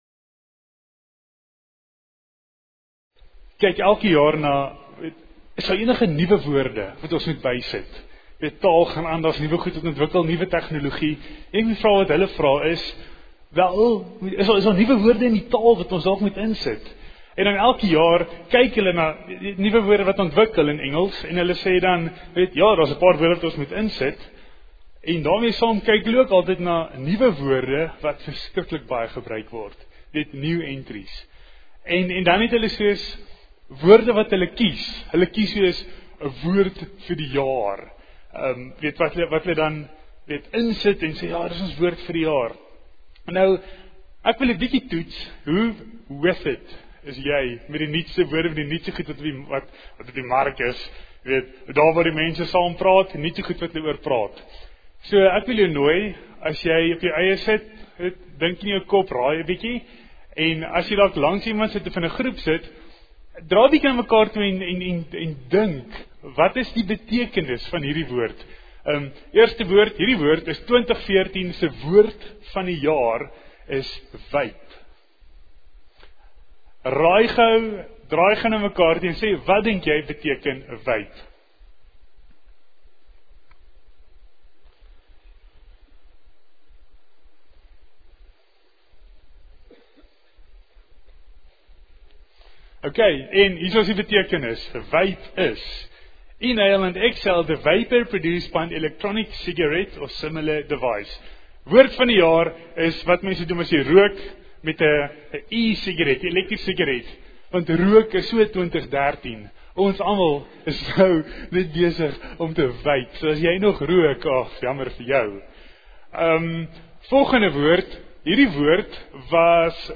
18:30 Aanhegsels Preeknota